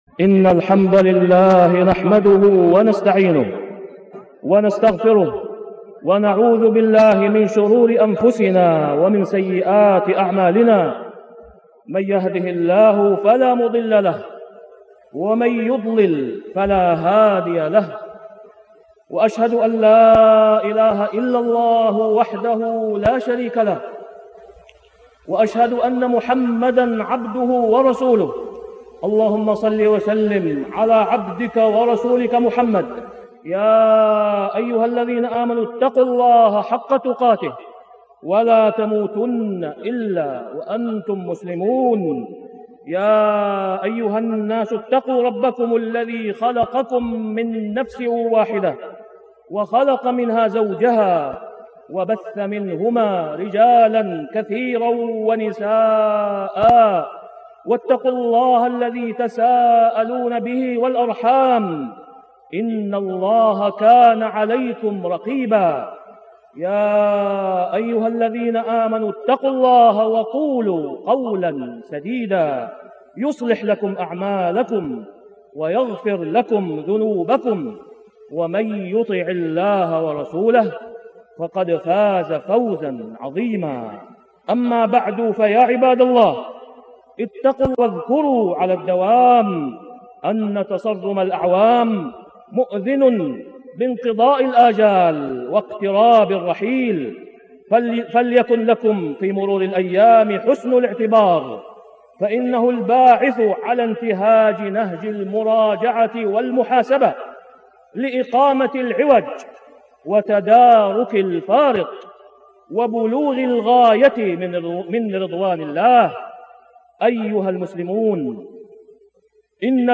تاريخ النشر ٢٧ ذو الحجة ١٤٢٦ هـ المكان: المسجد الحرام الشيخ: فضيلة الشيخ د. أسامة بن عبدالله خياط فضيلة الشيخ د. أسامة بن عبدالله خياط من وحي الهجرة